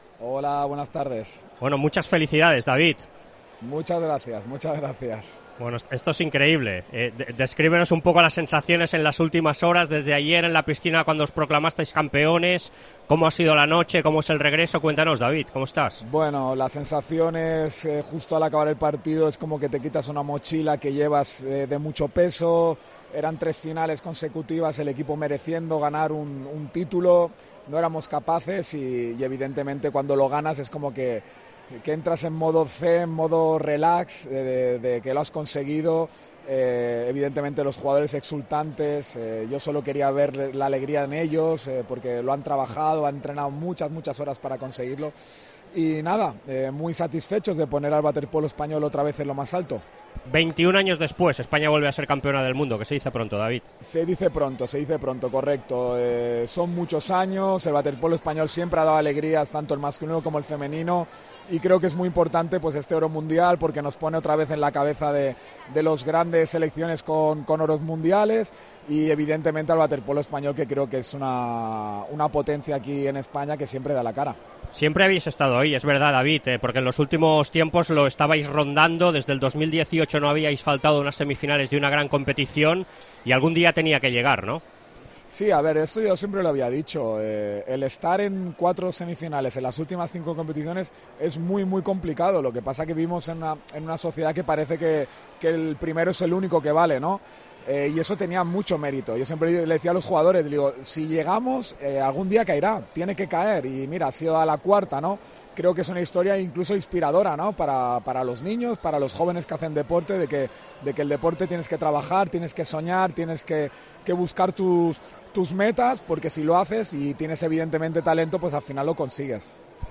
Escucha la entrevista con el seleccionador español de waterpolo tras proclamarse campeones del mundo en Budapest rompiendo el maleficio contra Italia en la final.